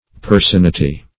Personeity \Per`son*e"i*ty\, n.